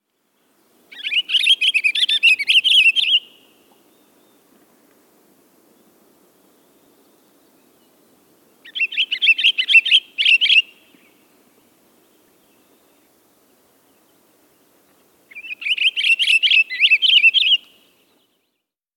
Pine Grosbeak
How they sound: Their song is a sequence of clear, warbling, flute-like notes.